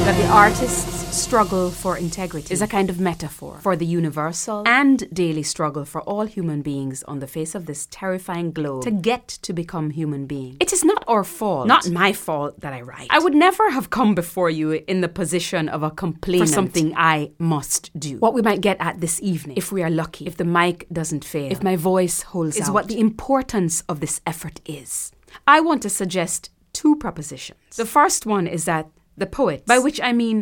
Basse (instrument)